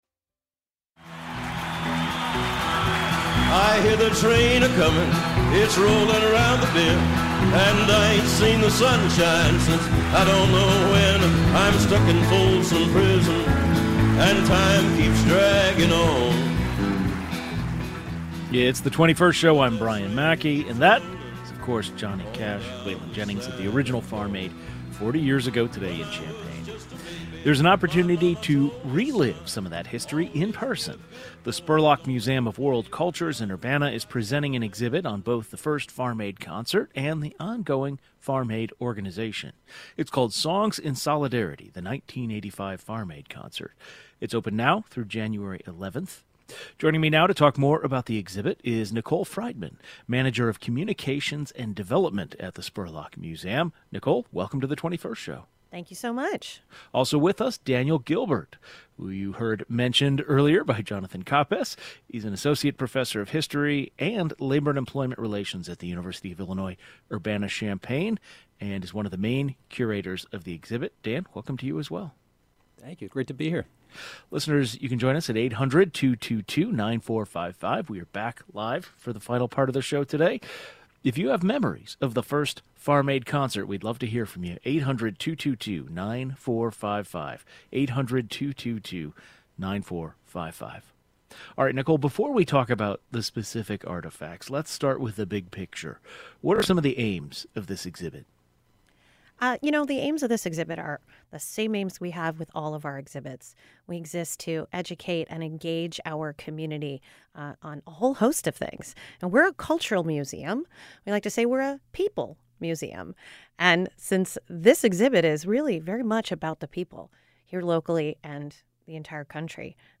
The 21st Show is Illinois' statewide weekday public radio talk show, connecting Illinois and bringing you the news, culture, and stories that matter to the 21st state.
Two representives from the museum, who are involved with the exhibit join the program.